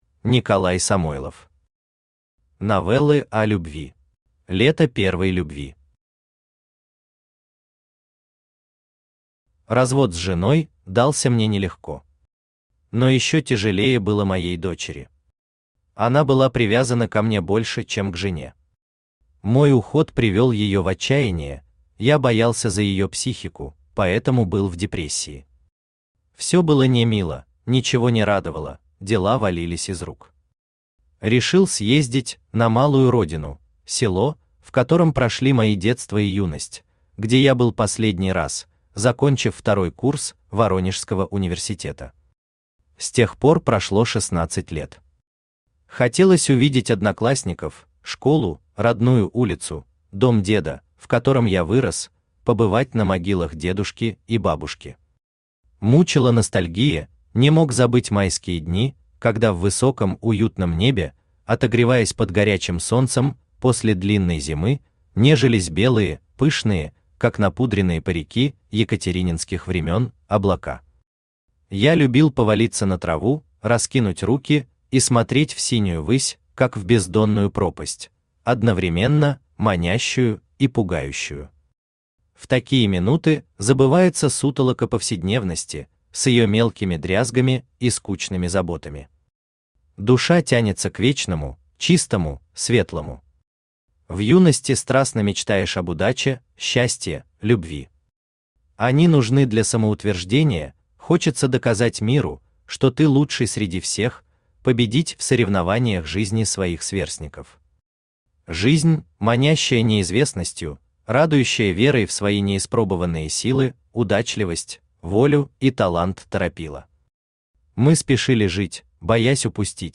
Аудиокнига Новеллы о любви | Библиотека аудиокниг
Aудиокнига Новеллы о любви Автор Николай Николаевич Самойлов Читает аудиокнигу Авточтец ЛитРес.